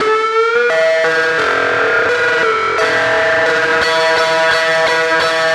Track 02 - Guitar Lead 02.wav